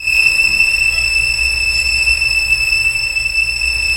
Index of /90_sSampleCDs/Roland LCDP13 String Sections/STR_Violins I/STR_Vls1 Symph